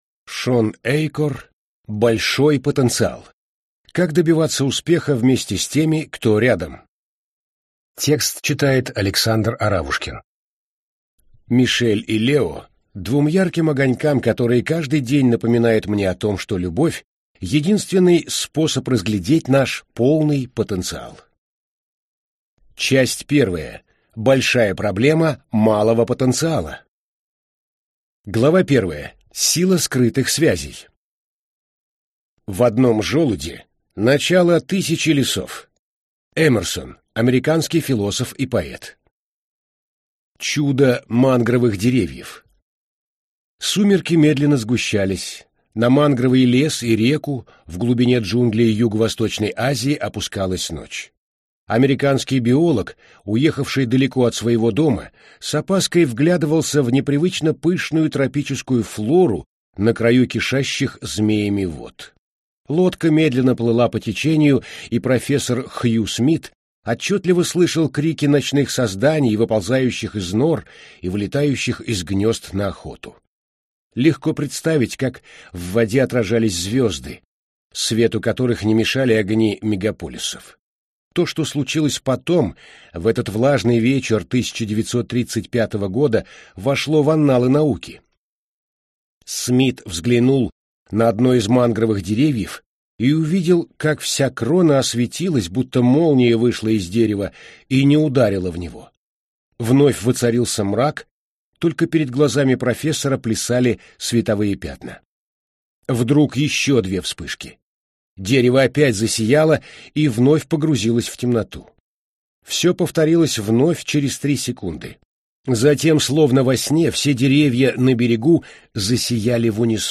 Аудиокнига Большой потенциал. Как добиваться успеха вместе с теми, кто рядом | Библиотека аудиокниг